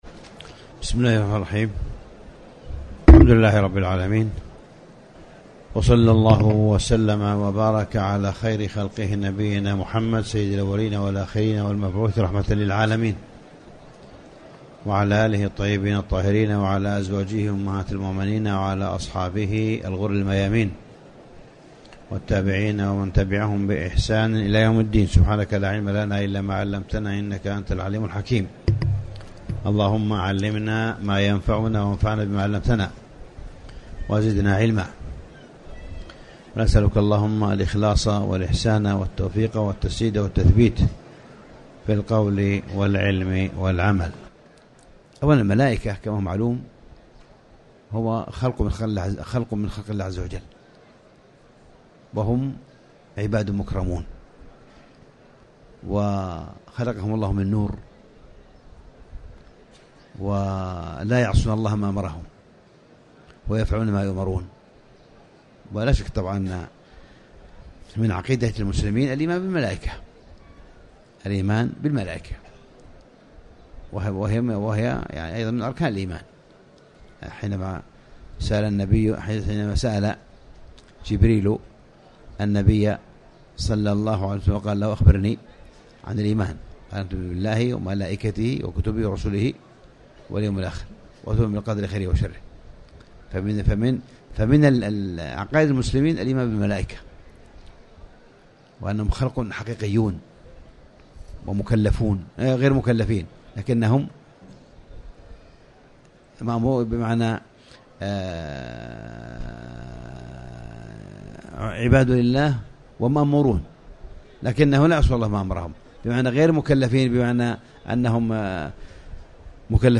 تاريخ النشر ٢٦ رمضان ١٤٣٩ المكان: المسجد الحرام الشيخ: معالي الشيخ أ.د. صالح بن عبدالله بن حميد معالي الشيخ أ.د. صالح بن عبدالله بن حميد التذكير بتحري ليلة القدر